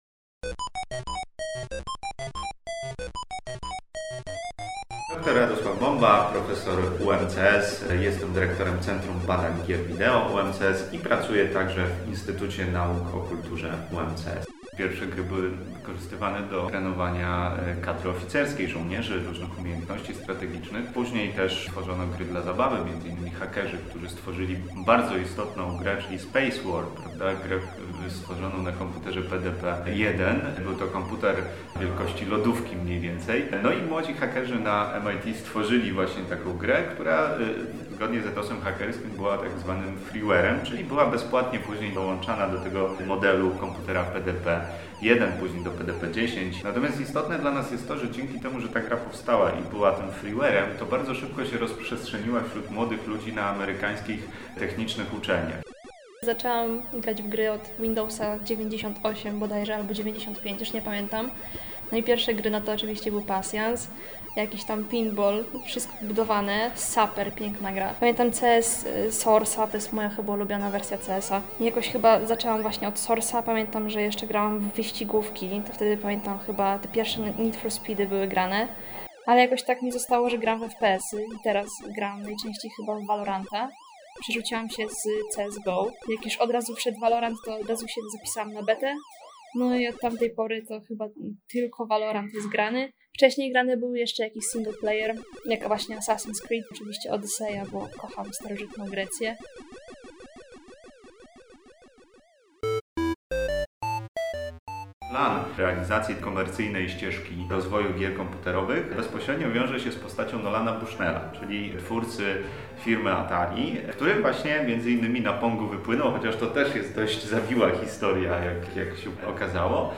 Z kolei gracze opowiedzieli o tym, od czego zaczynali swoją przygodę w wirtualnych światach, w co grają obecnie oraz co gry wniosły do ich życia.